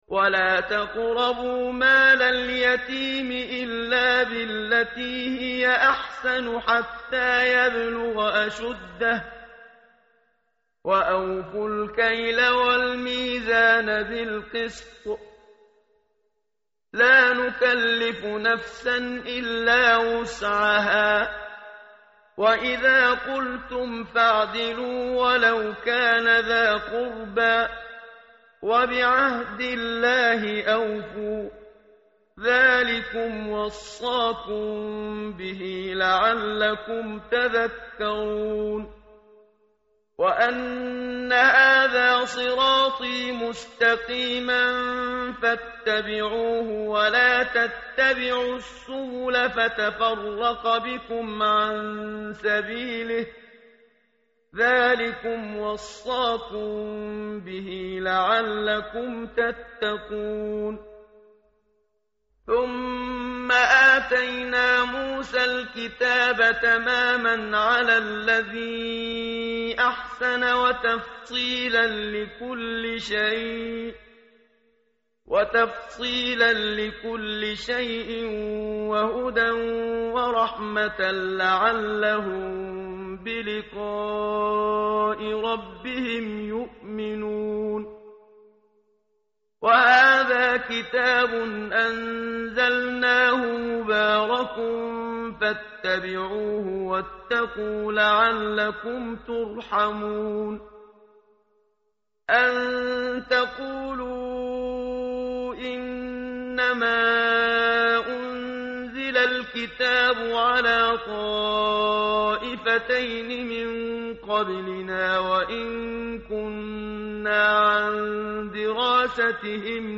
tartil_menshavi_page_149.mp3